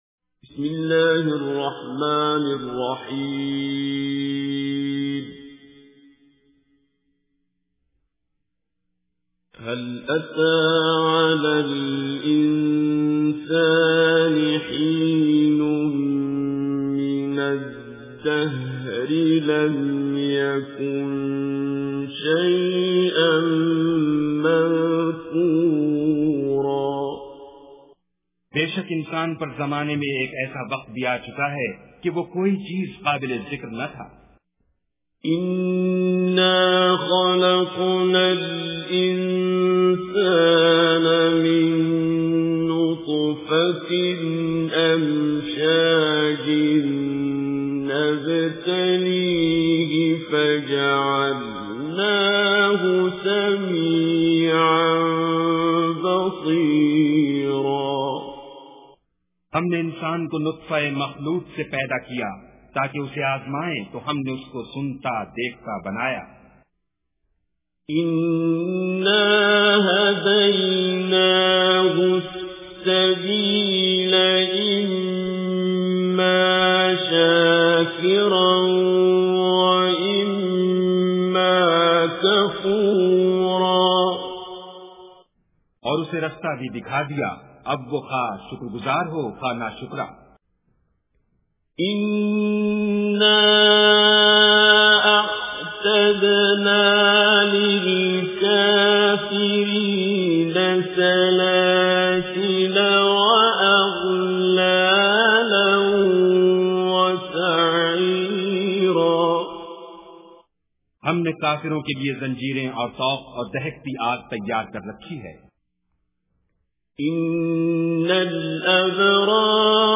Surah Ad-Dahr Recitation with Urdu Translation
Surah Ad-Dahr or Surah Al Insan is 76 chapter of Holy Quran. Listen online and download mp3 tilawat / recitation of Surah Insan in the beautiful voice of Qari Abdul Basit As Samad.